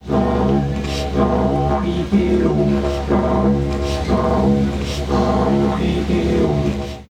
Instrumento aborigen: didgeridoo
aerófono